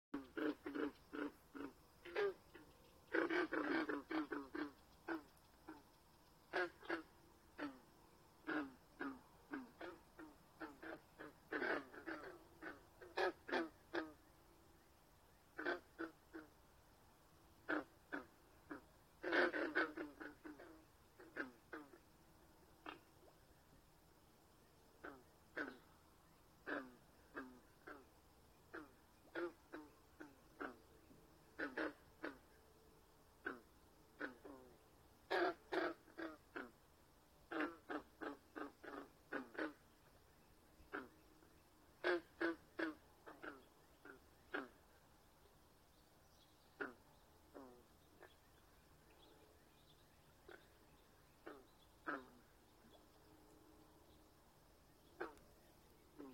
In rare instances and warm conditions, Vermonters may hear the Green Frog (Lithobates clamitans) calling in small numbers at the end of April. Their calls often sound like plucking at a loose banjo string or a single gulping sound.